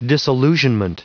Prononciation du mot disillusionment en anglais (fichier audio)